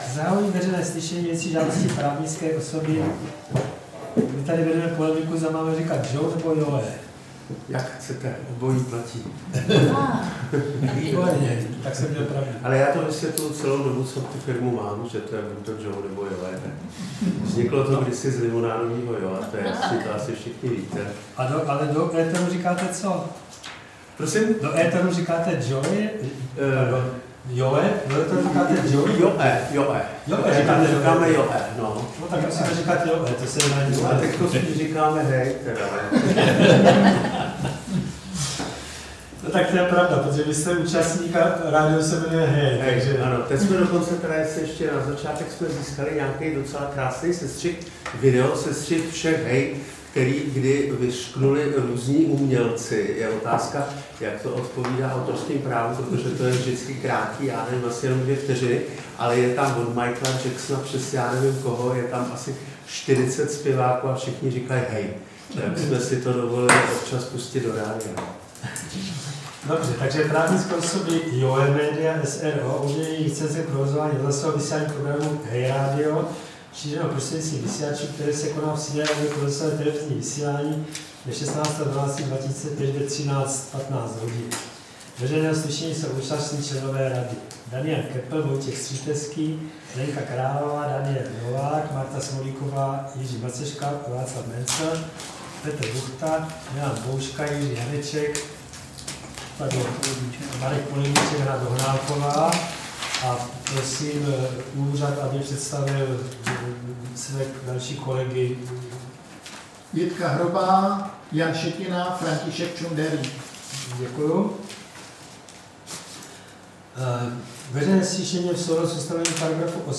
Veřejné slyšení v řízení o udělení licence k provozování rozhlasového vysílání šířeného prostřednictvím vysílačů se soubory technických parametrů Písek 89,5 MHz/500 W
Z veřejného slyšení se pořizuje zvukový záznam.